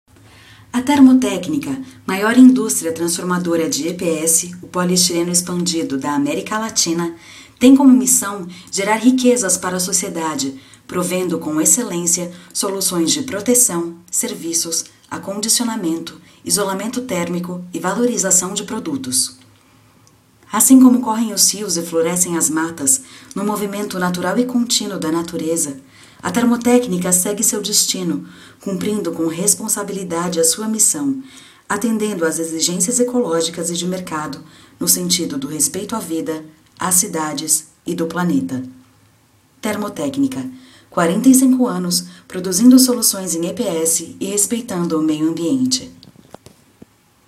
Sprechprobe: Werbung (Muttersprache):
Brazilian actress, voice over, dubbing, singer